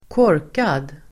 Uttal: [²k'år:kad]